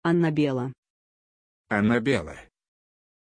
Pronuncia di Annabella
pronunciation-annabella-ru.mp3